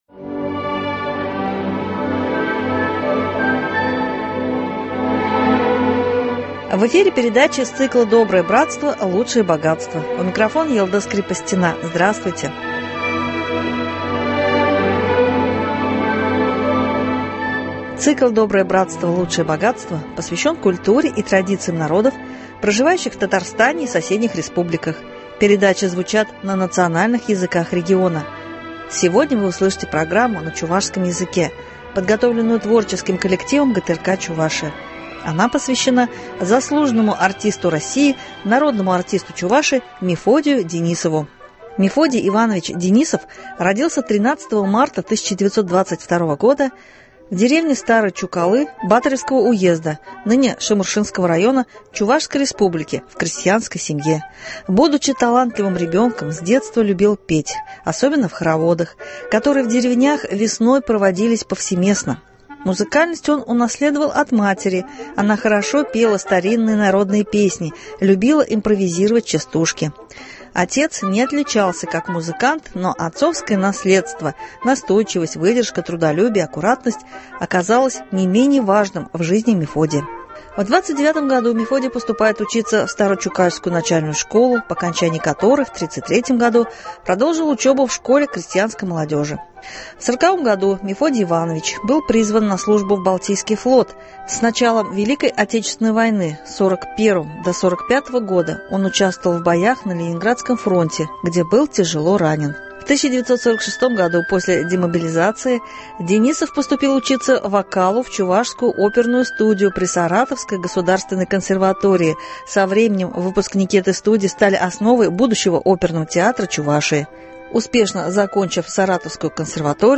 Литературно-музыкальная программа на чувашском языке.